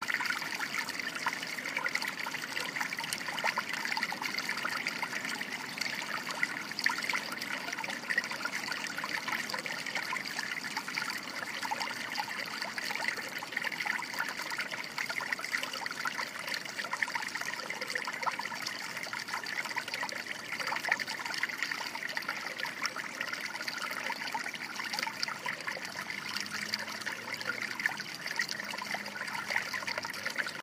户外自来水
描述：排水沟正在下雨
Tag: 河川 潺潺 跑步 户外